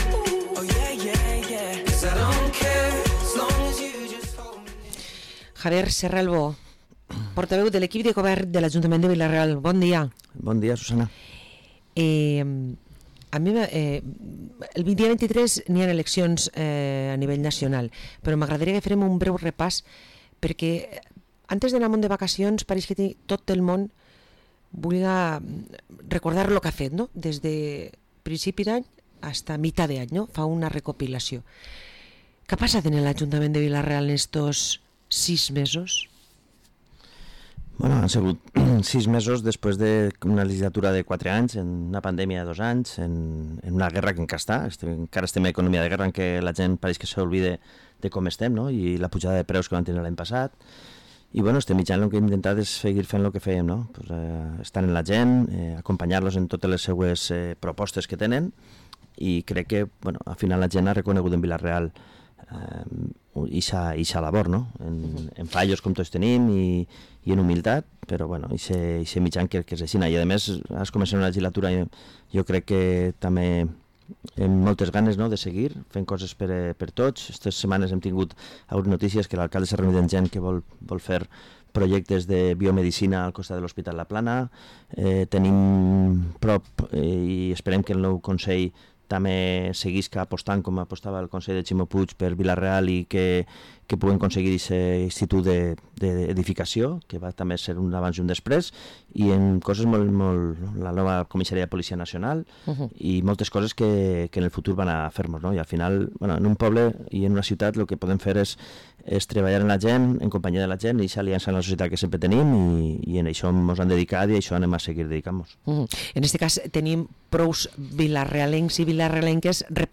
Parlem amb Javier Serralvo, portaveu de l´equip de govern a l´ajuntament de Vila-real